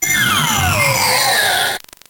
Fichier:Cri 0609 NB.ogg